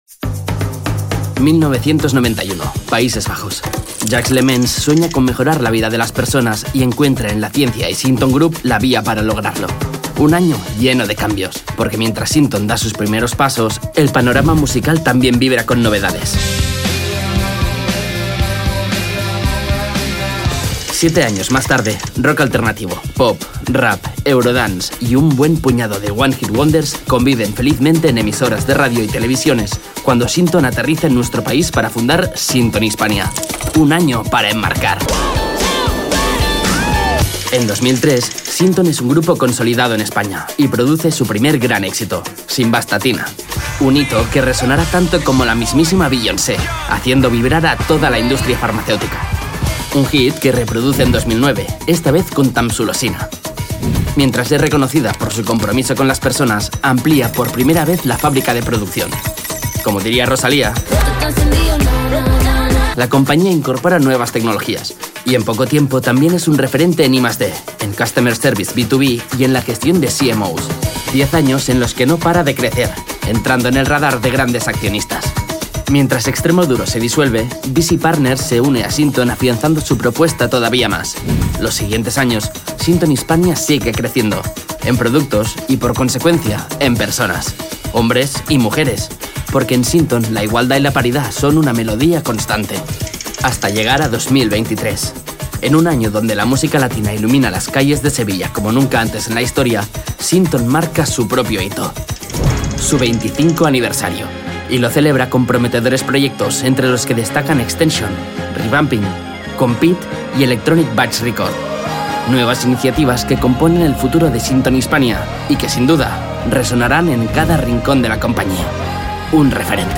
sehr variabel
Jung (18-30)
Eigene Sprecherkabine
Commercial (Werbung)